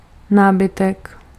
Ääntäminen
Ääntäminen Tuntematon aksentti: IPA: /møːbel/ Haettu sana löytyi näillä lähdekielillä: ruotsi Käännös Ääninäyte 1. nábytek {m} Artikkeli: en .